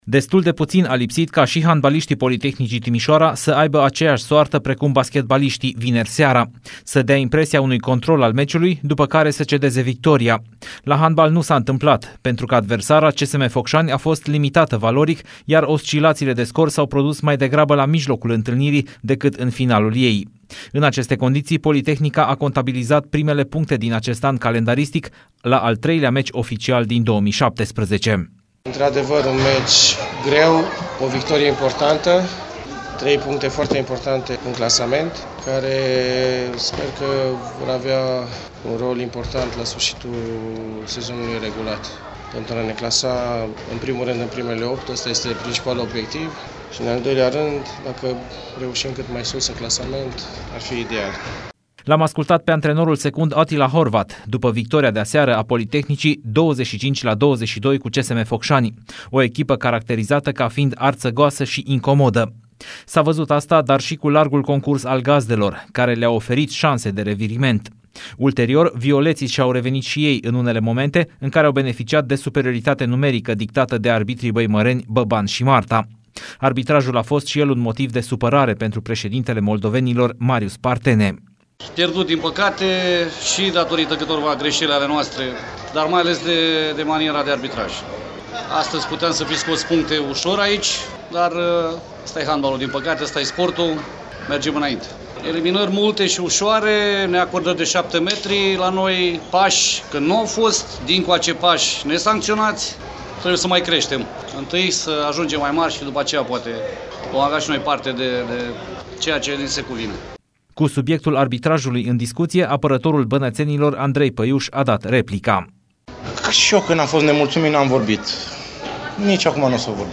Reportaj-victorie-chinuita-la-handbal.mp3